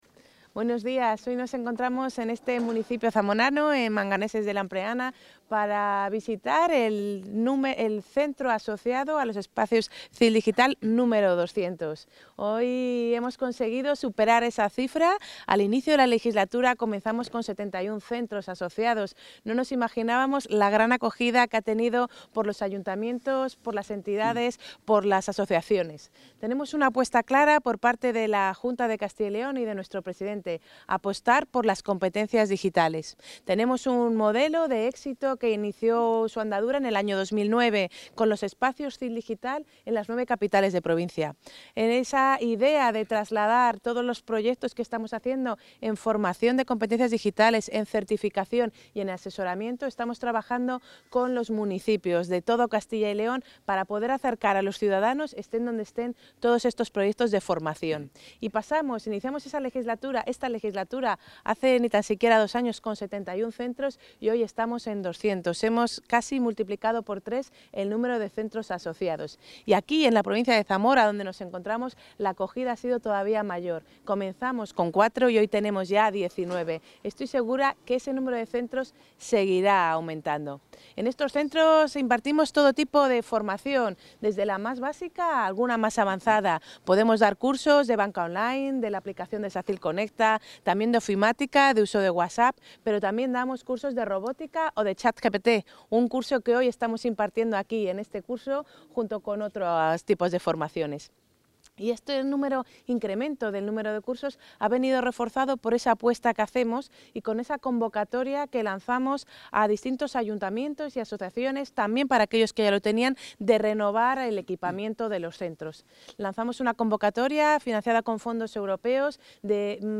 La consejera de Movilidad y Transformación Digital, María González Corral, ha visitado hoy en la localidad zamorana de...
Intervención de la consejera.